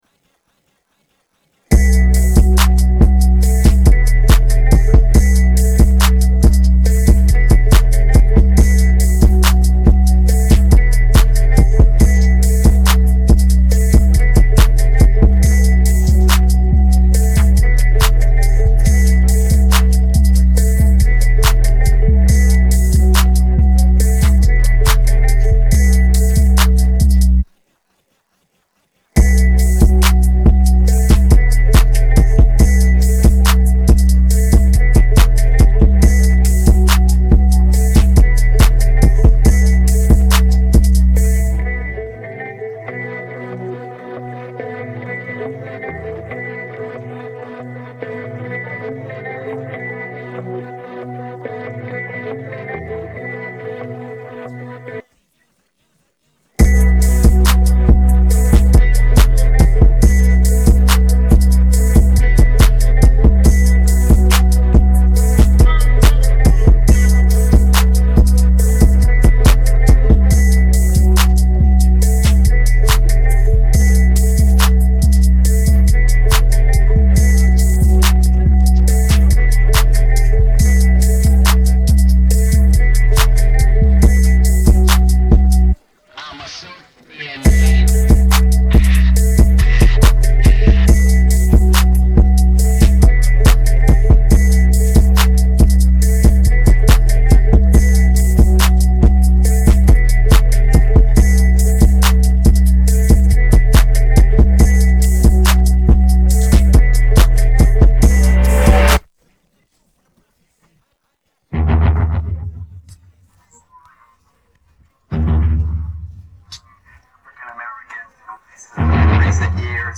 Фонова музика